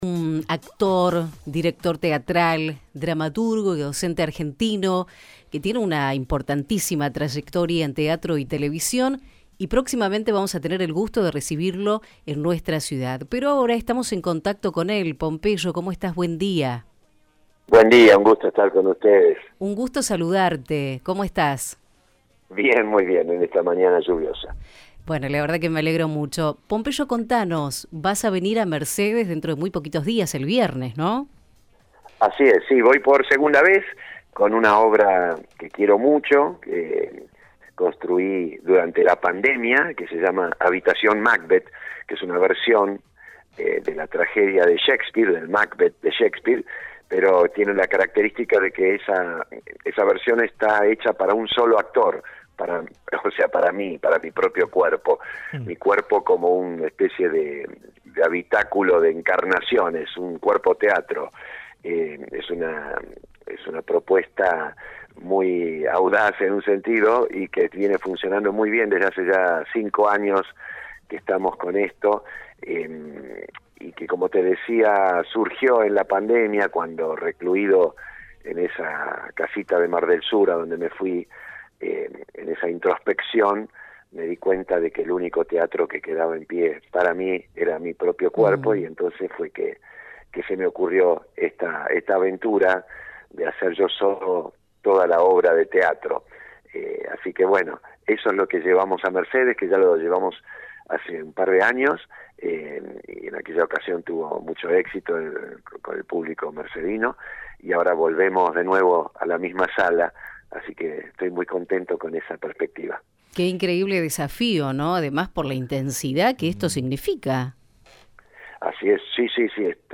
Pompeyo Audivert en Rdio Universo